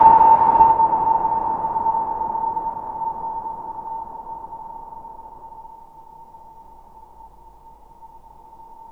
SONAR.wav